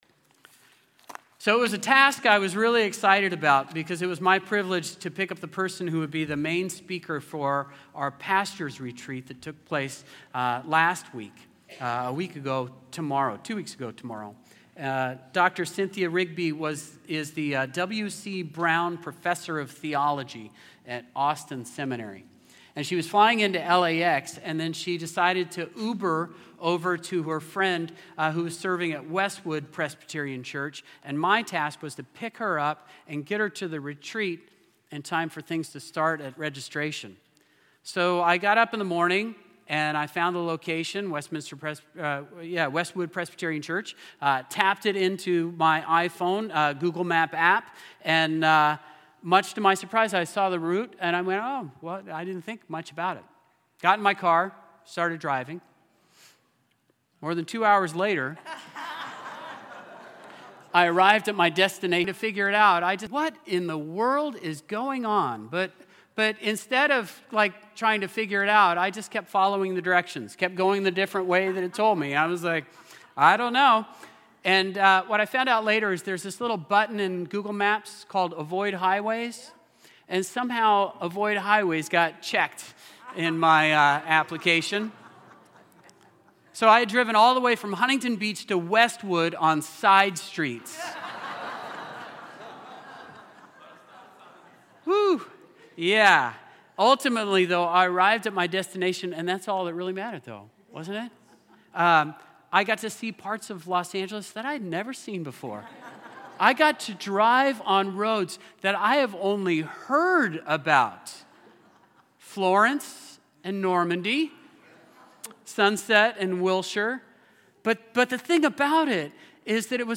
Play Rate Listened List Bookmark Get this podcast via API From The Podcast You are listening to the St. Peter's By-the-Sea Presbyterian Church sermon podcast.